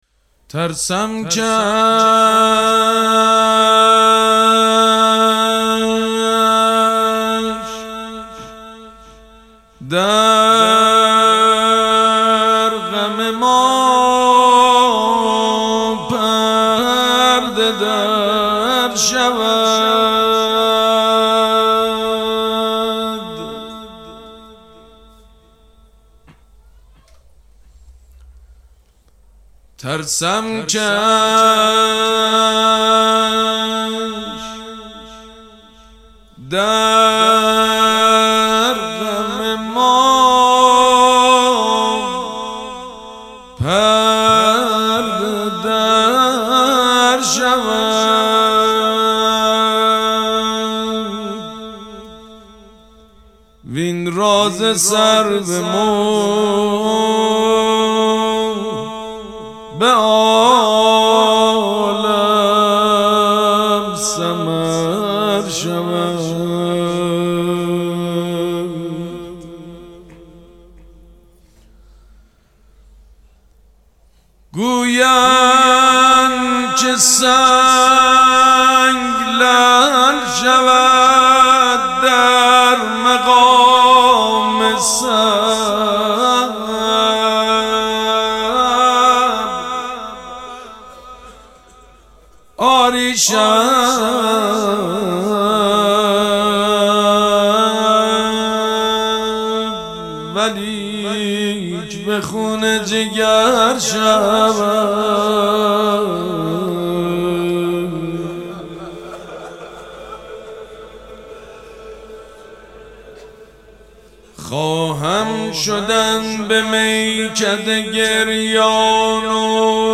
مداحی به سبک شعرخوانی اجرا شده است.